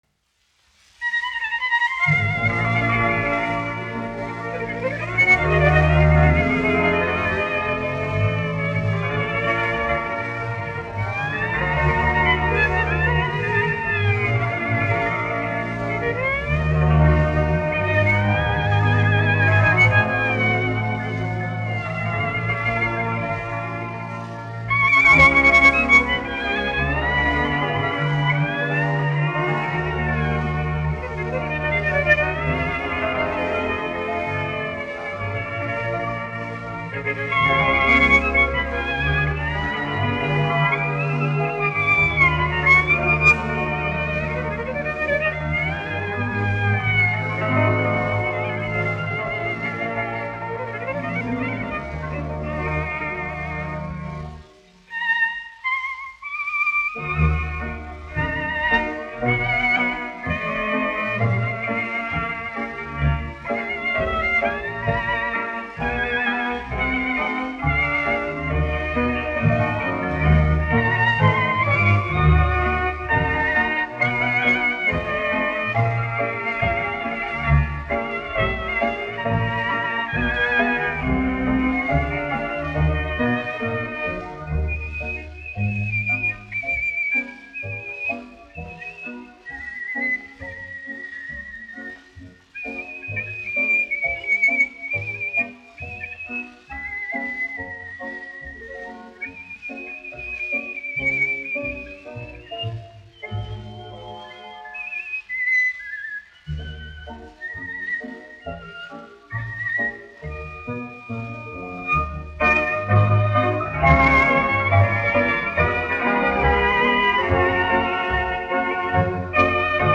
1 skpl. : analogs, 78 apgr/min, mono ; 25 cm
Tautas deju mūzika -- Ungārija
Tautas mūzika--Ungārija
Skaņuplate